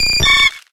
Audio / SE / Cries / BUTTERFREE.ogg